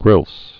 (grĭls)